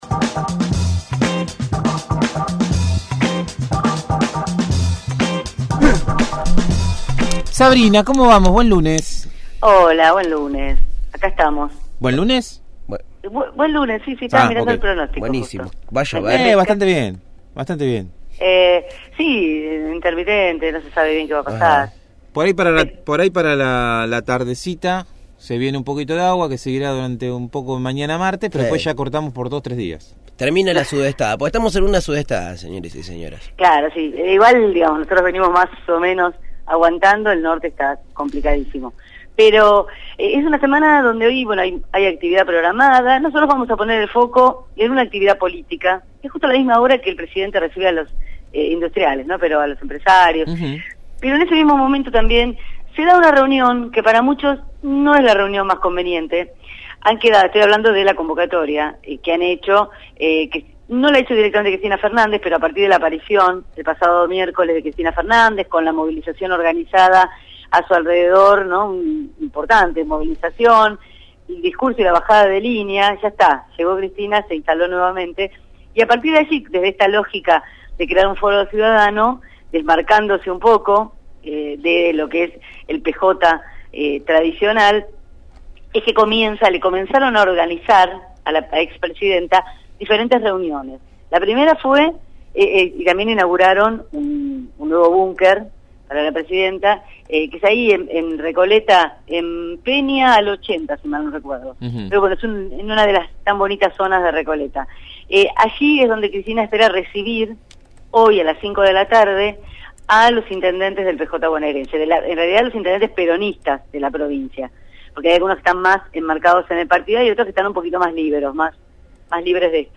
realizó su habitual informe sobre la actualidad política bonaerense.